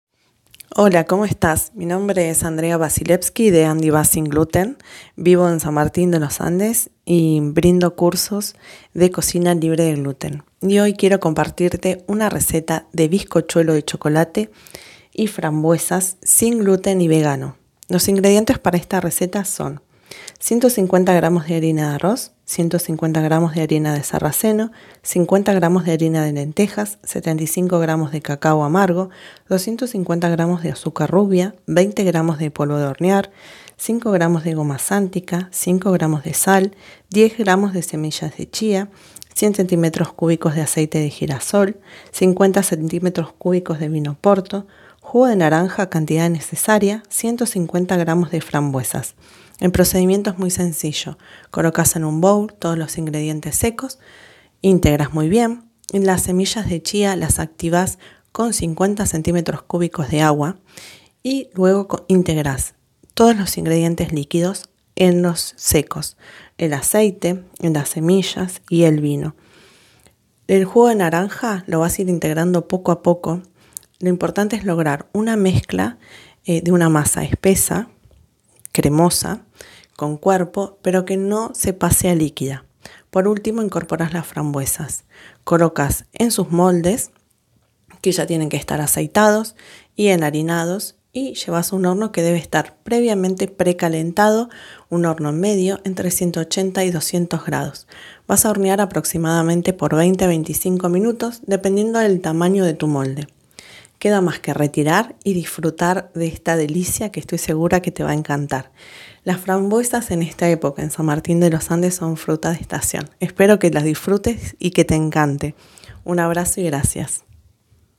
Audio receta de bizcochuelo de chocolate y frambuesa sin gluten y vegano